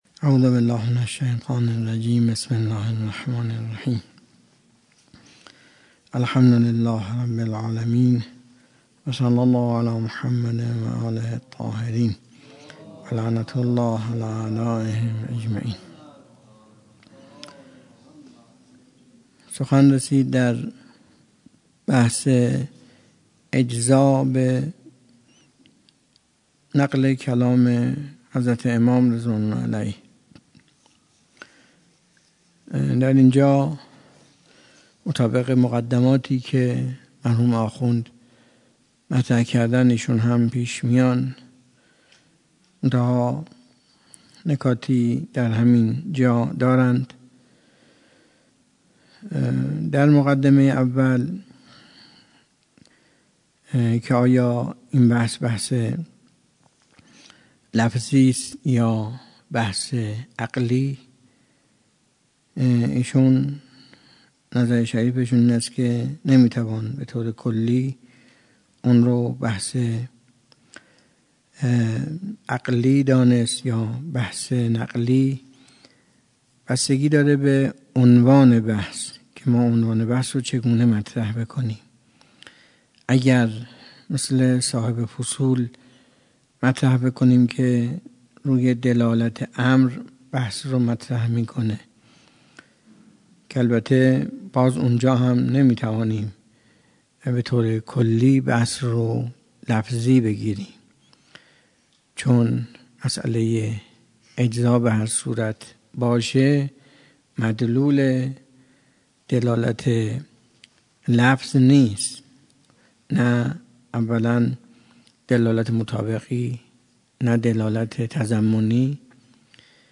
درس خارج اصول آیت الله تحریری - تاریخ 97.08.29